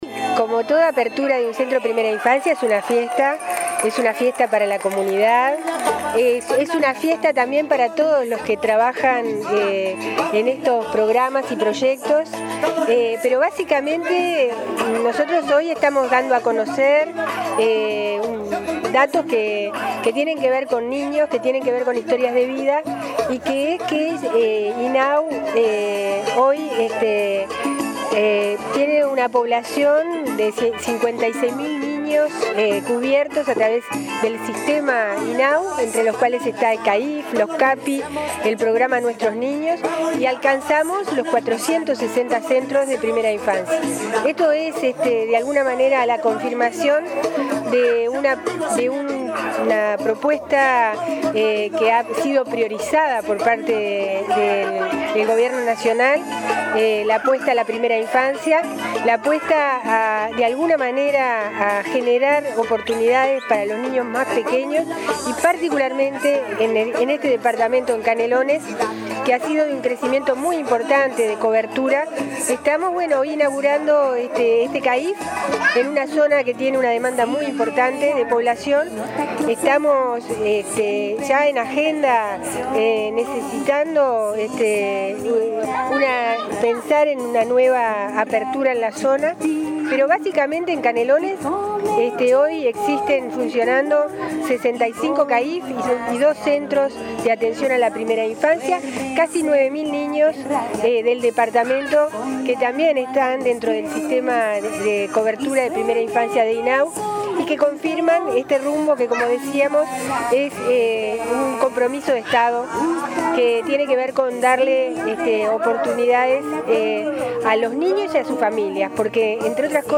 “Esta es la confirmación de la propuesta de generar oportunidades a niños más pequeños que ha sido priorizada por el Gobierno”, subrayó la presidenta de INAU, Marisa Lindner, durante la inauguración del CAIF “Niños de la Costa” en Canelones.